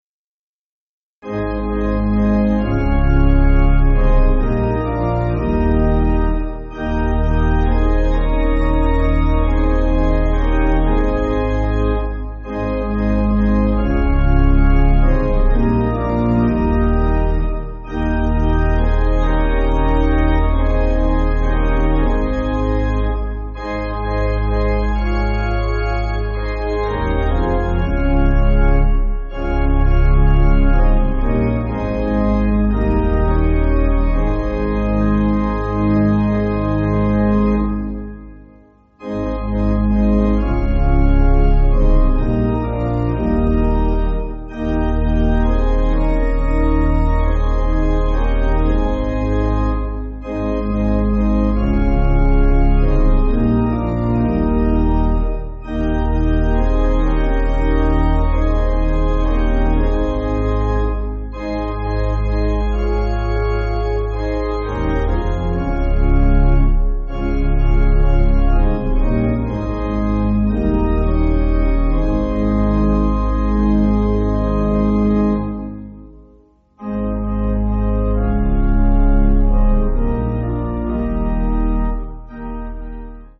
(CM)   3/Ab